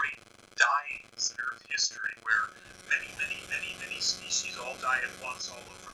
Broken, Glitchy Audio
I think it might be worse than it looks in waveform.
The whole way through the recording is filled with an awful glitchy cracking noise, that no amount of noise removal can get rid of without making the actual voice incomprehensible.
I’m using Windows 10, and was recording from a USB microphone plugged directly into the port.